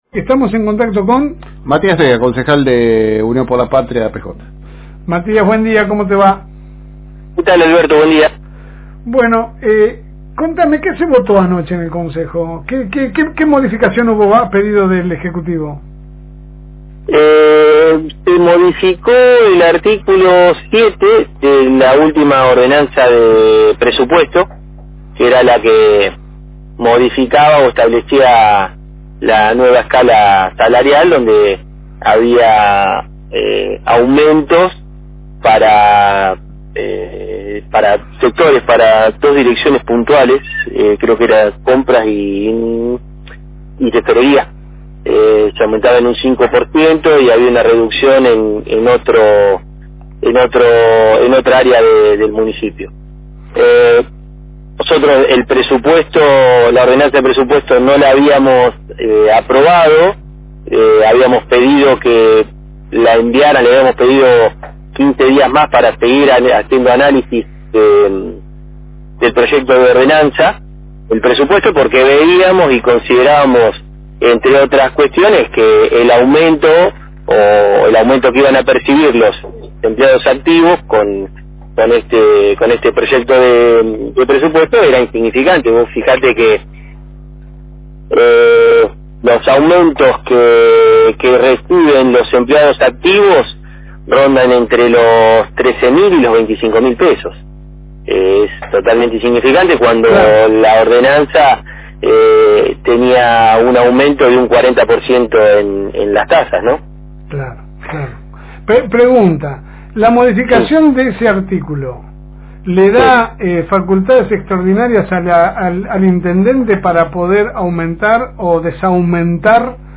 Para saber que paso dentro del recinto llamamos a los concejales Matías Thea de Unión por la Patria y el concejal Luciano Re de la Unión Vecinal Conservada que nos contaban que fue lo que se votó anoche.
El audio de la nota es bastante largo porque están los dos testimonios juntos, en orden de aparición primero esta la charla con el concejal Thea y la segunda mitad del audio el concejal Re.